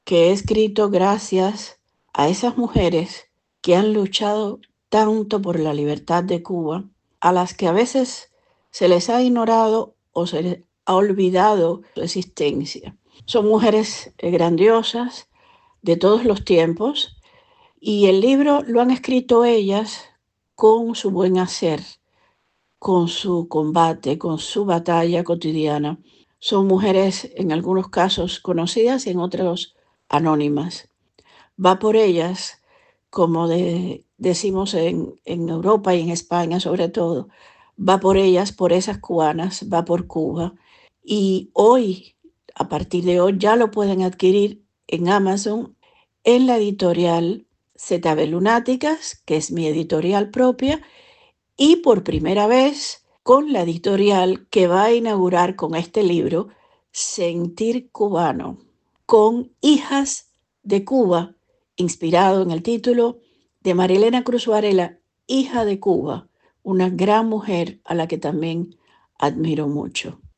Declaraciones de Zoé Valdés a Radio Martí